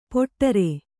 ♪ poṭṭaṇare